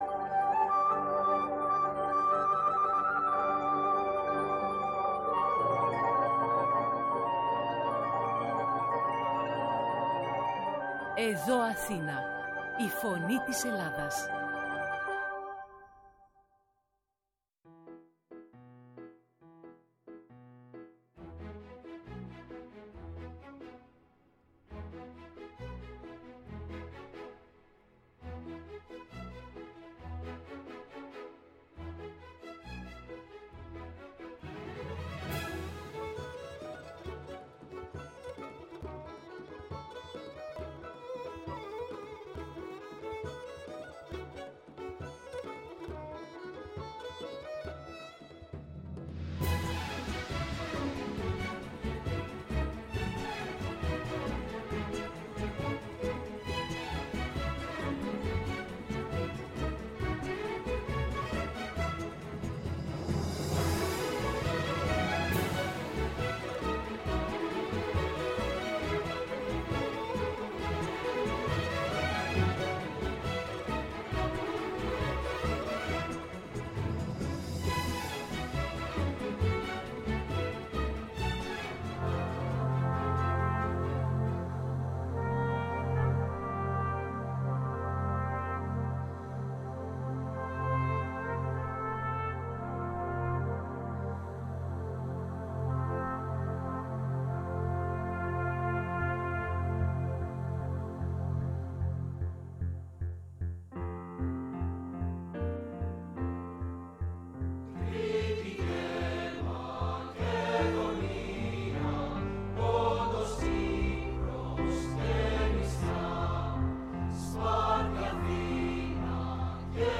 Τα ζητήματα που άπτονται των θαλάσσιων ζωνών στη Νοτιοανατολική Μεσόγειο και τα θέματα που αφορούν στη σύγχρονη έρευνα, για την ανακάλυψη υδρογονανθράκων, τέθηκαν στο “μικροσκόπιο” της εκπομπής «Έλληνες παντού», στο Παγκόσμιο Ραδιόφωνο της ΕΡΤ «Η Φωνή της Ελλάδας», την Τετάρτη, 21 Απριλίου 2021.